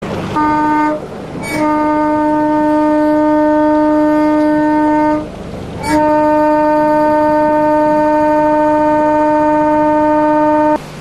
Klingelton / Signalton „Typhon“
Das Typhon der Eye of the Wind dient als akustisches Signal bei Kursänderungen, als Warnung im Nebel oder einfach als freundlicher Gruß von Schiff zu Schiff, mit dem man sich gegenseitig eine gute Weiterreise wünscht. Nutzen Sie den Klang des Typhons als unverwechselbaren maritimen Klingelton, Signalton oder Weckton für Ihr Mobiltelefon.